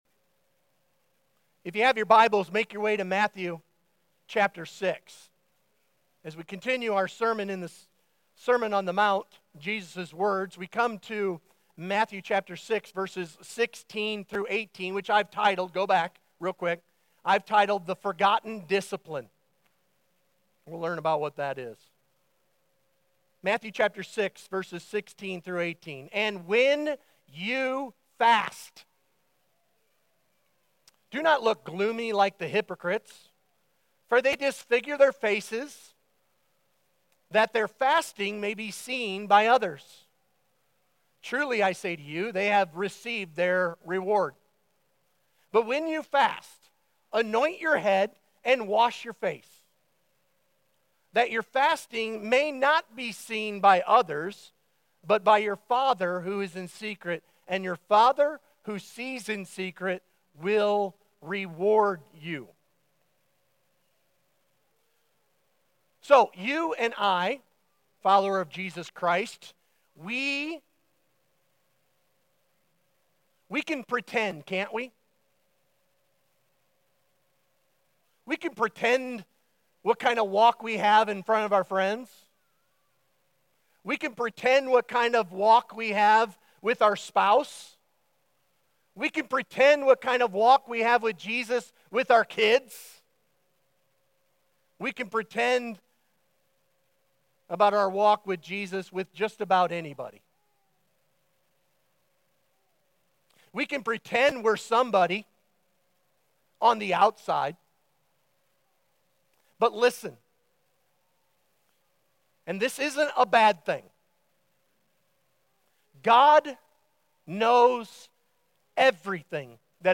The Forgotten Discipline | High Pointe Church